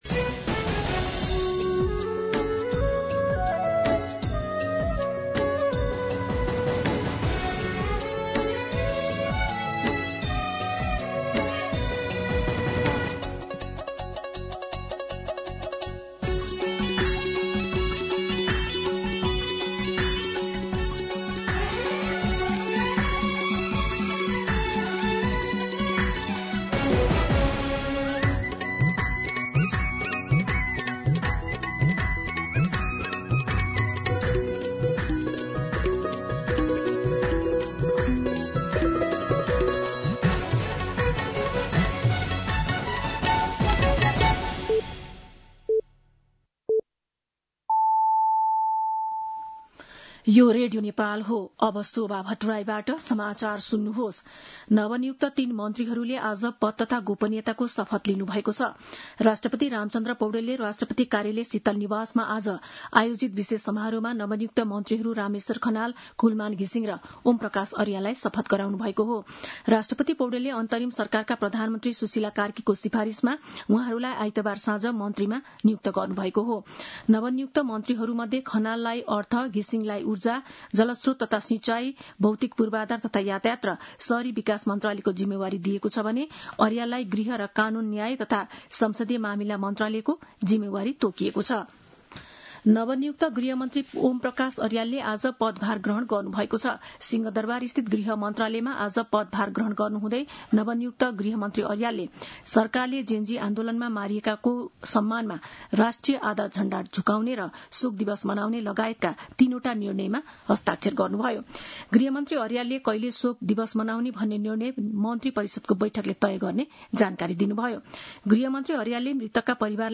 दिउँसो ४ बजेको नेपाली समाचार : ३० भदौ , २०८२